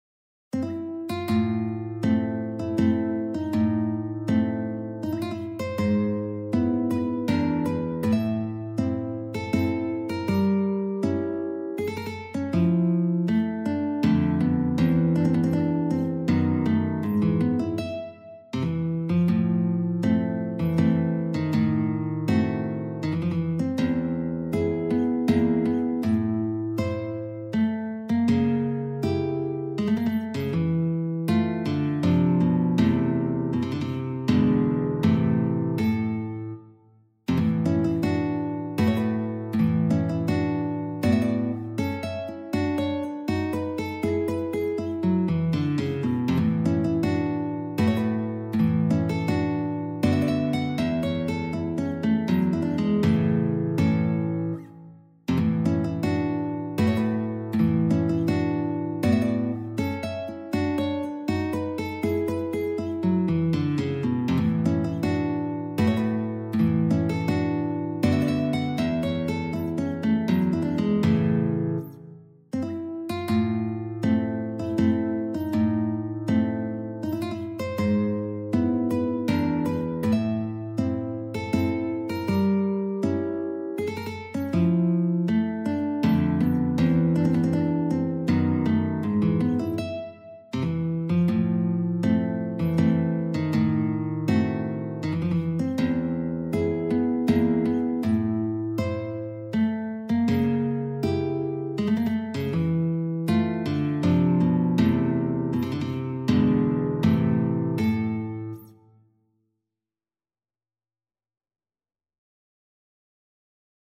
Guitar  (View more Advanced Guitar Music)
Classical (View more Classical Guitar Music)